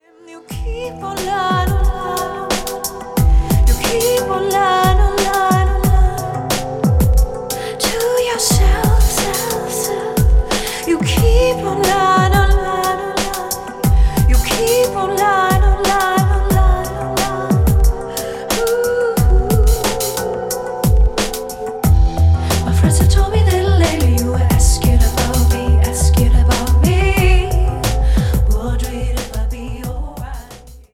• Качество: 320, Stereo
женский вокал
спокойные
лирические
soul
ритм-энд-блюз